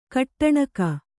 ♪ kaṭṭaṇaka